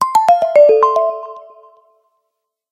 nokia-lumia-alert-calendar_24522.mp3